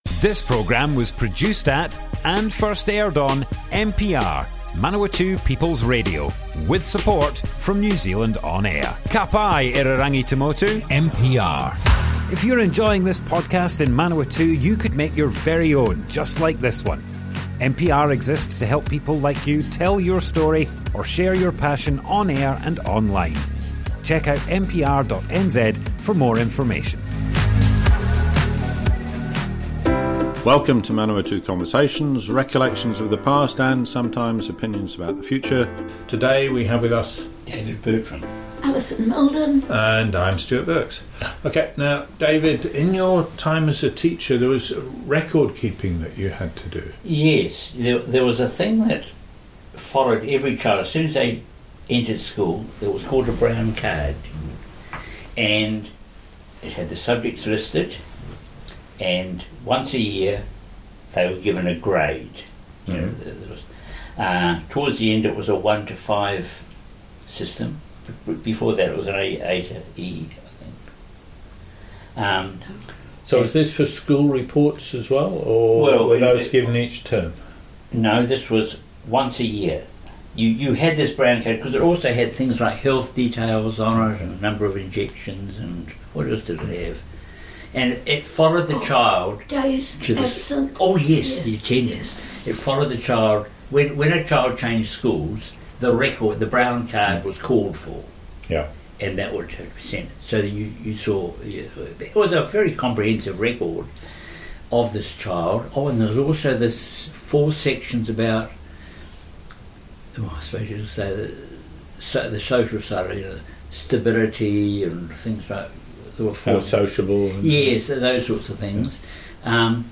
Manawatu Conversations More Info → Description Broadcast on Manawatu People's Radio, 21st March 2023.
oral history